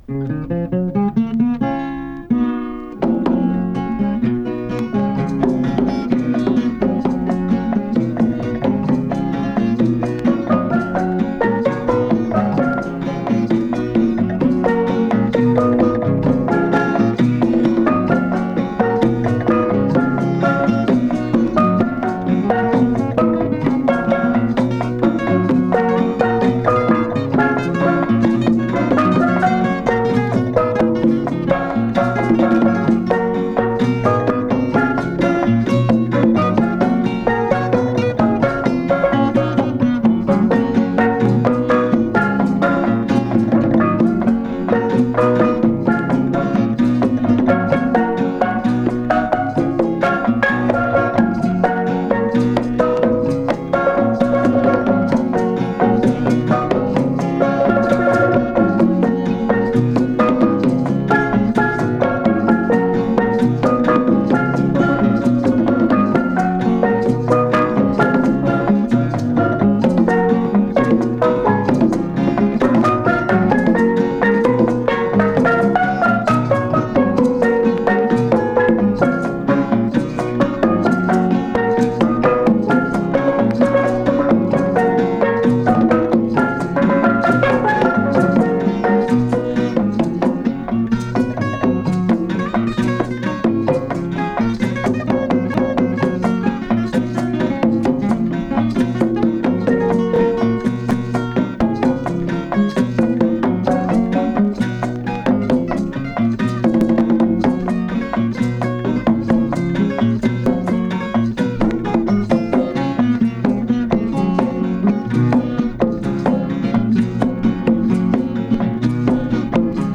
Support : 33 tours 13 cm